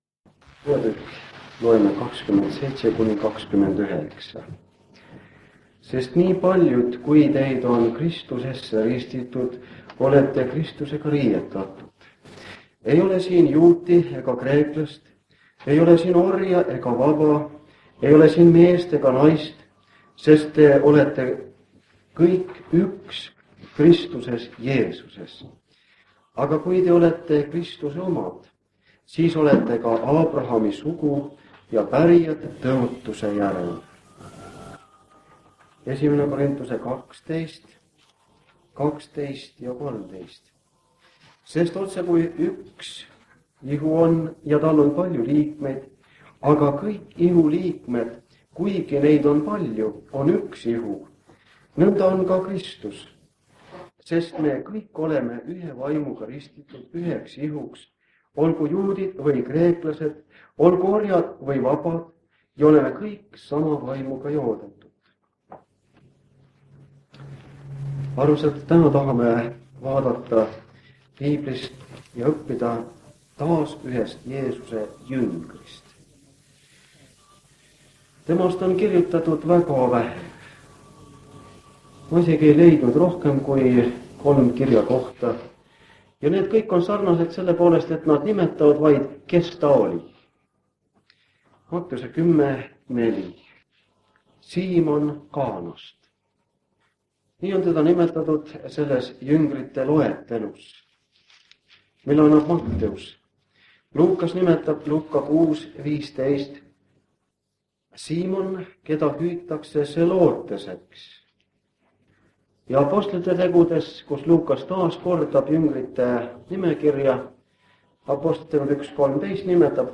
Kõne vanalt lintmaki lindilt aastast 1983.
Kuuleme ka koorilaule.
Jutlused